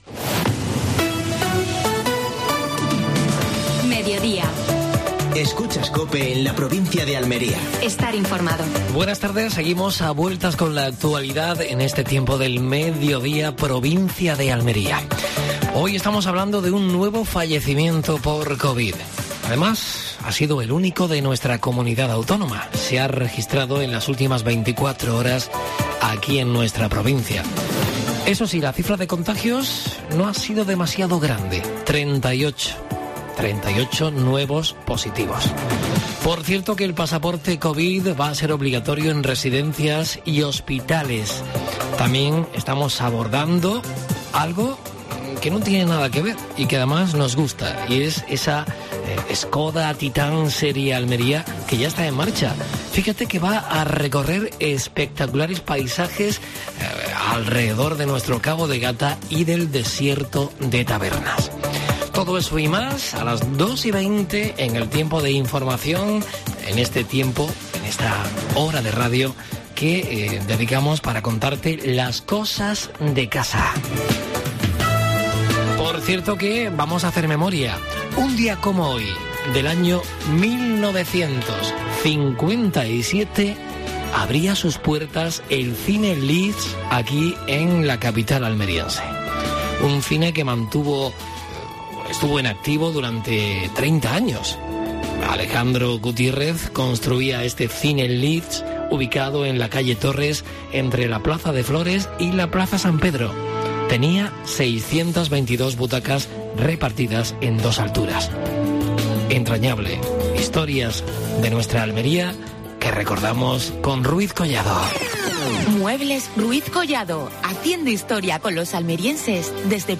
AUDIO: Actualidad en Almería. Entrevista a María Luisa Cruz (diputada provincial). Última hora deportiva.